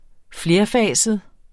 Udtale [ ˈfleɐ̯ˌfæˀsəð ]